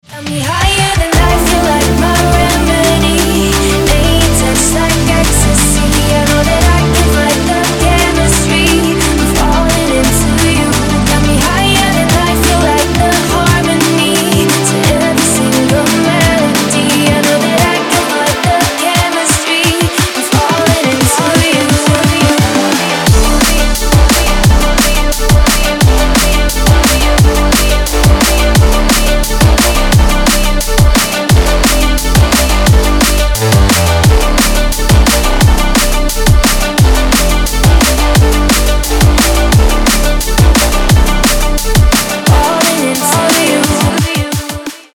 • Качество: 320, Stereo
энергичные
красивый женский голос
ремиксы
драм энд бейс
Стиль: drum and bass